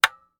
clock_tick.mp3